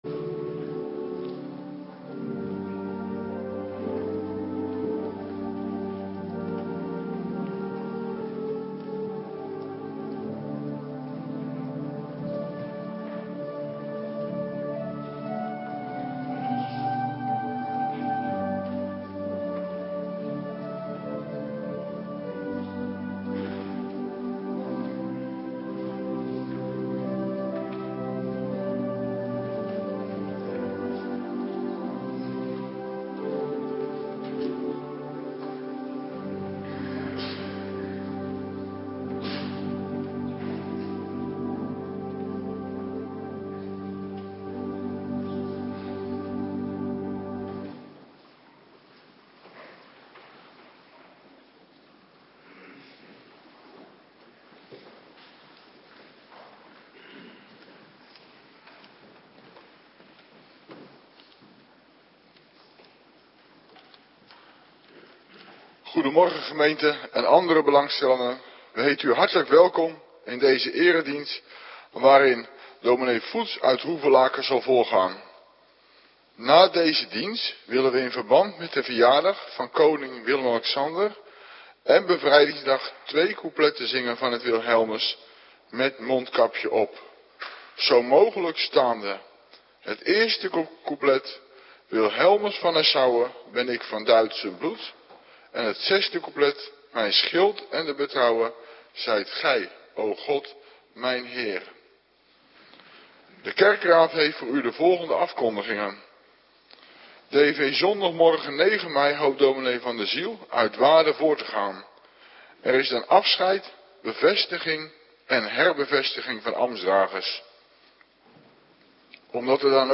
Morgendienst - Cluster 2
Locatie: Hervormde Gemeente Waarder
Preek over Num. 17: 8 en 9